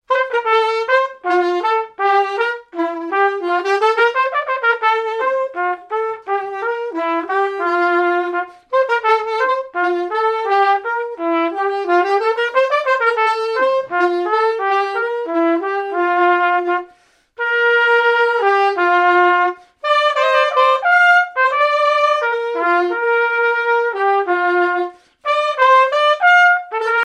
Marche nuptiale n° 1
fiançaille, noce
Pièce musicale inédite